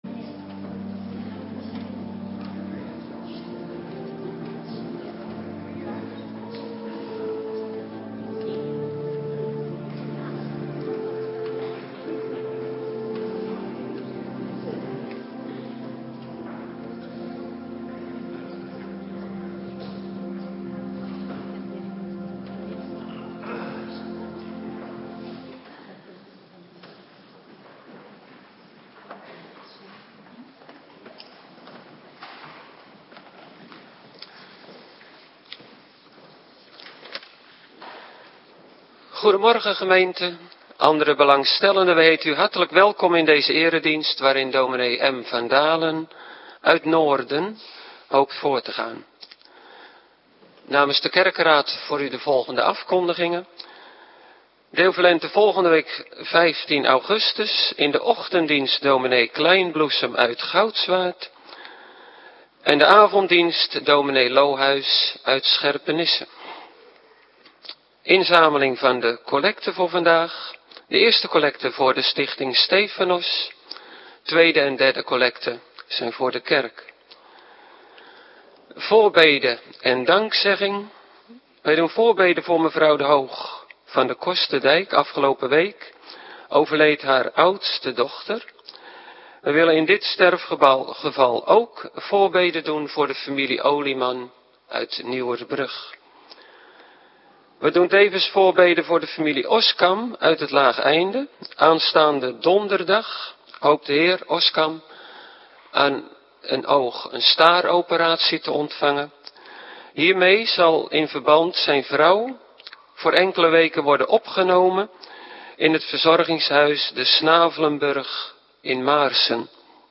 Morgendienst - Cluster B